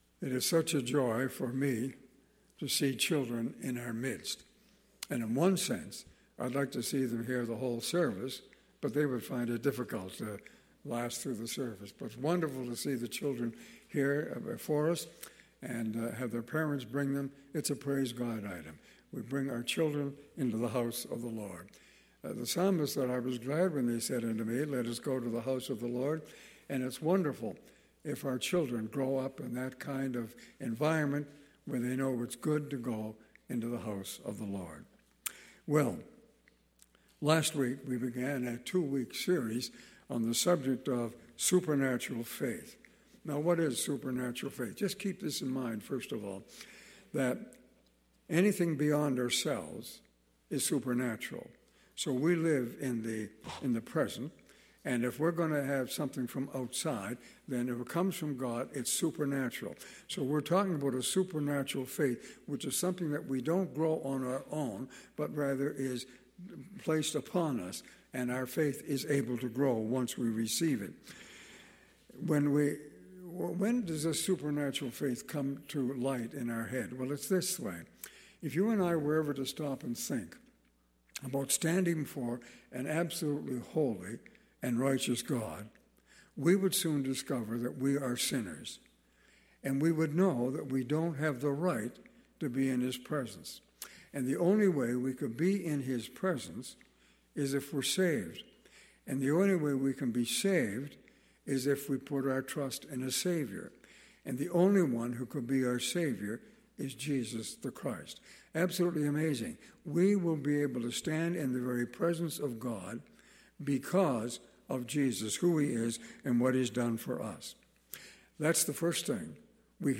Audio Sermons - Campbellford Baptist Church Inc.